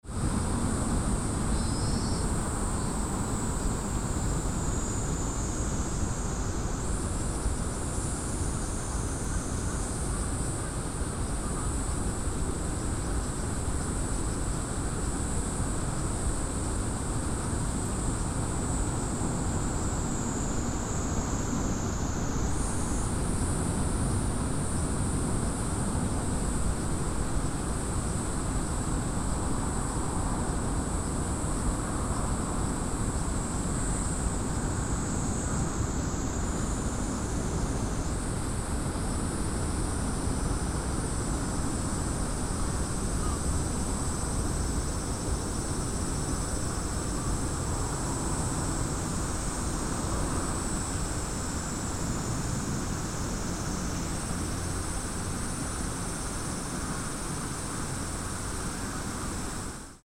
Even before the end of the rainy season, cicadas began to make loud noises in Shinhama Park. ♦ All the children in the park were playing in the rest house with air conditioning.